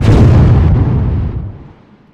Fireworks And Crowd
Fireworks in distance and some spanish dude yelling bravo!
Great celebration and crowd sounds.